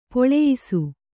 ♪ poḷeyisu